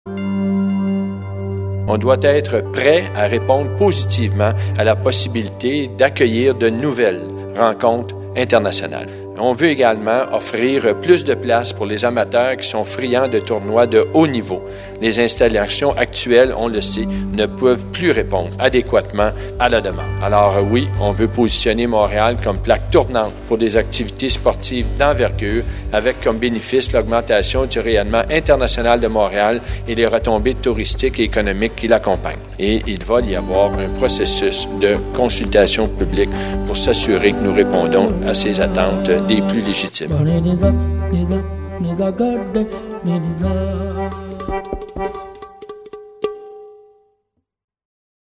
Reportage 48'